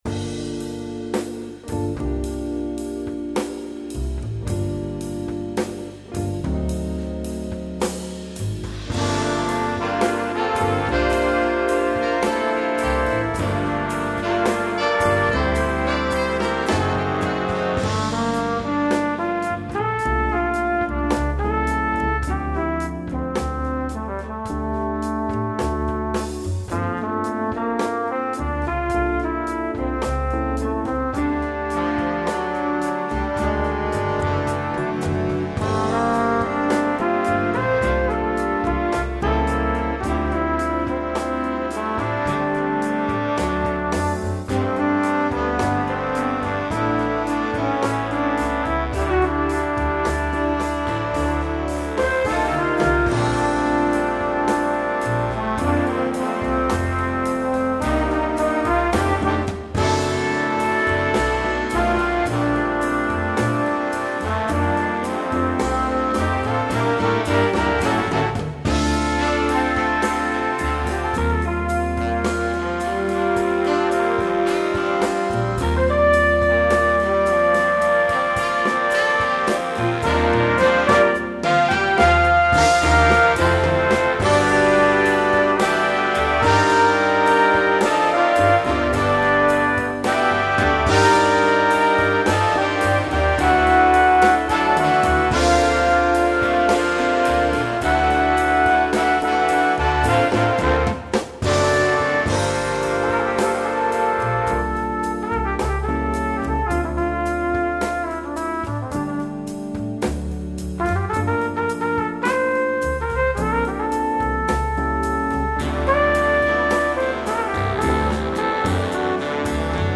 Voicing: Trumpet w/BB